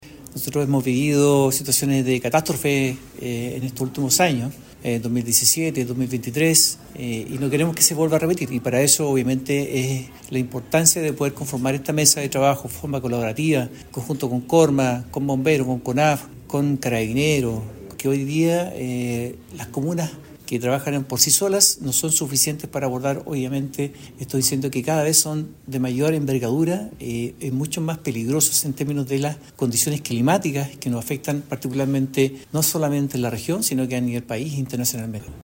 En tanto, Ítalo Cáceres, alcalde de Tomé, llamó al gobierno central para que apoye esta iniciativa, porque las comunas del Biobío han sido duramente golpeadas. Espera que más alcaldes se sumen con el fin de prevenir y no solo reaccionar después de la catástrofe.